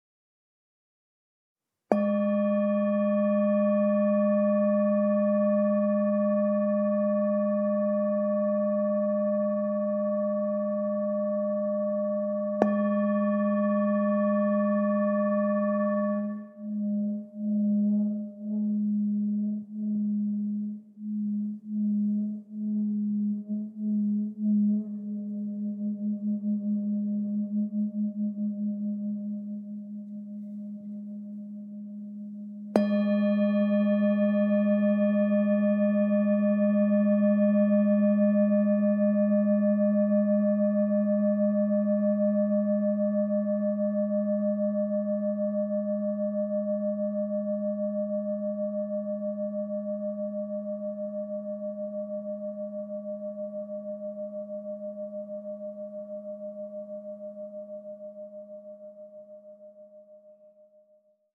Meinl Sonic Energy Cosmos Series Singing Bowl - 800g (SB-C-800)
When softly tapped or rubbed, Sonic Energy singing bowls release a fascinating, multi-layered, and colorful sound that resonates deeply within the soul. Over a rich fundamental tone, entire waterfalls of singing overtones emerge to float freely in space and unfurl inside the body. Once the sound starts vibrating, it won't stop; even a minute later, a soft reverberation can still be felt.
With a bellied design created from additional hammering, these bowls generate a sustained deep tone that will fill a room with ambient sounds.